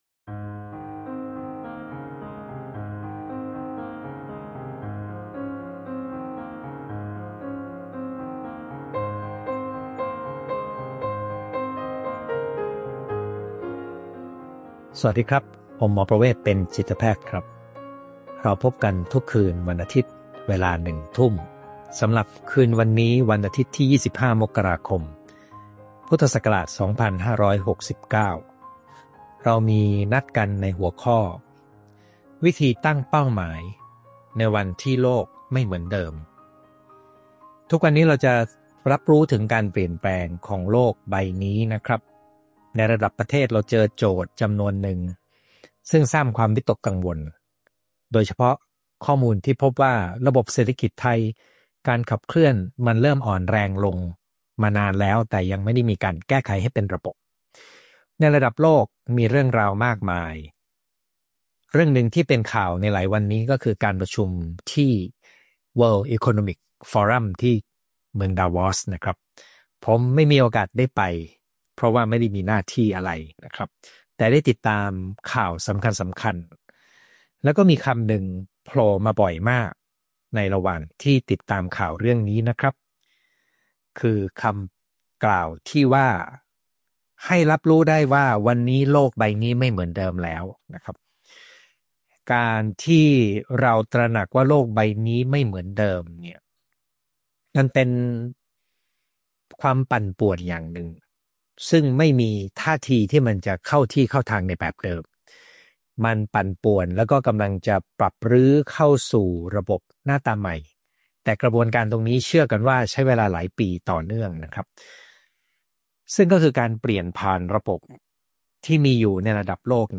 ไลฟ์ประจำวันอาทิตย์ที่ 25 มกราคม 2569 เวลาหนึ่งทุ่ม